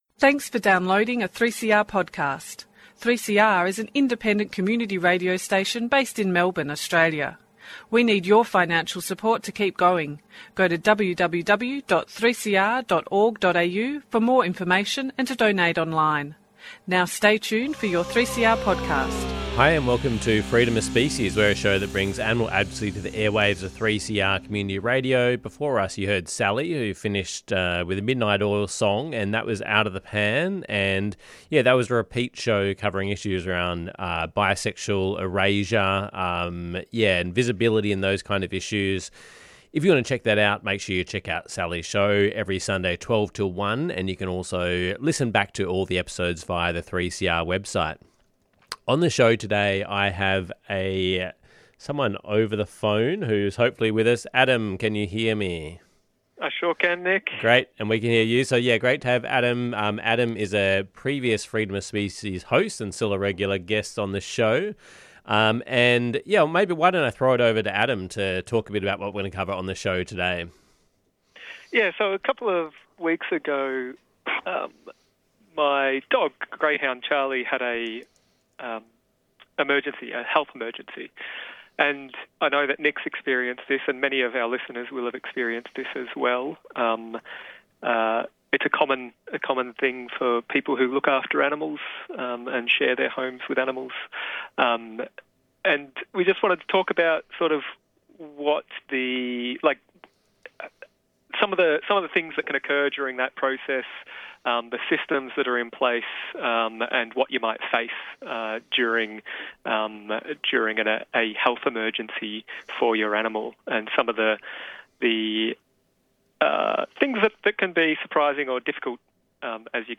Animal advocacy on the airwaves, hosted by a team of local animal advocates.